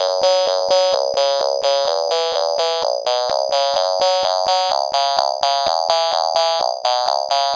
Squeaky Cheeze F# 127.wav